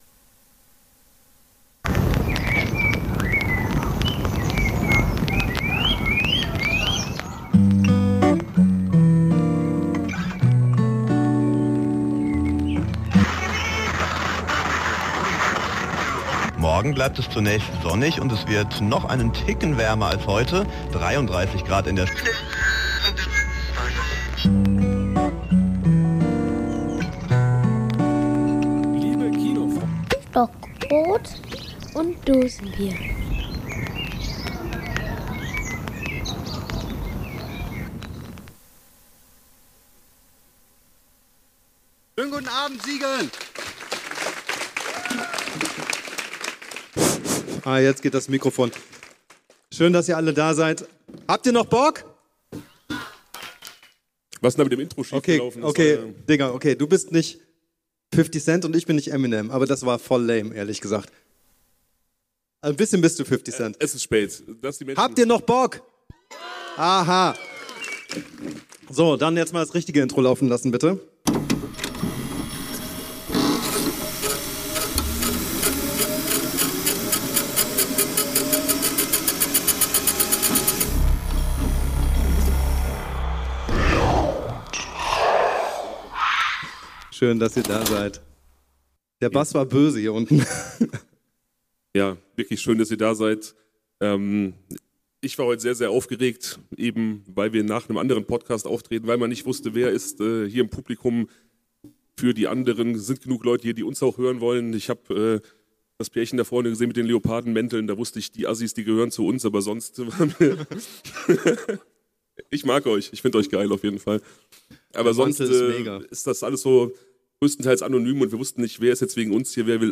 Blutrausch live im Siegener Open Air Kino